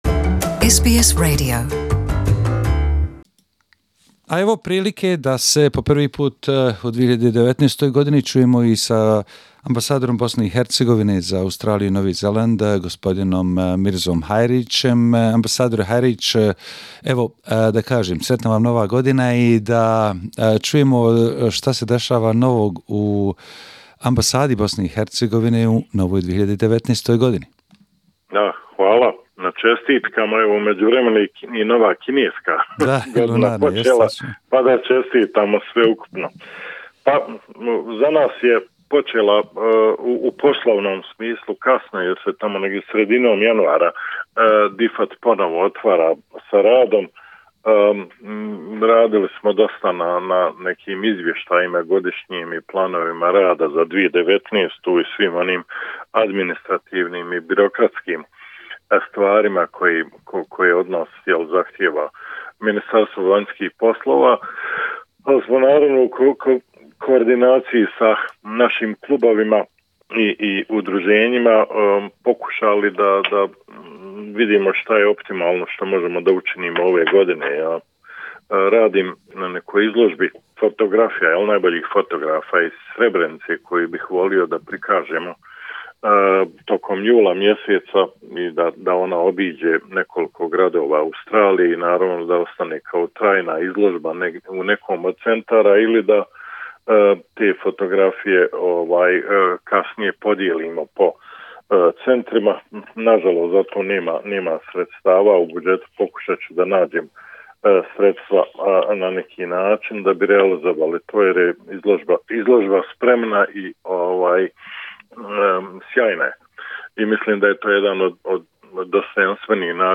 Ambassador of Bosnia and Herzegovina in Canberra HE Mirza Hajric- Interview